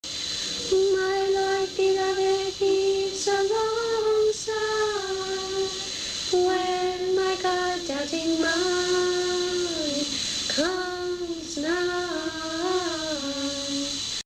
See individual song practice recordings below each score.